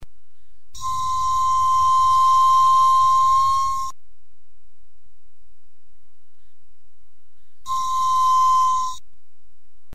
TINAMUS GUTTATUS
Ouça o piado. O macho pia “mais agudo” que a fêmea, é um pouco menor, tem mais pintinhas brancas nas penas, principalmente das asas e sempre me pareceu que o macho é dominante, pois, normalmente, é quem aparece na frente, quando vem acasalado.